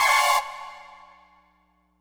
synth note.wav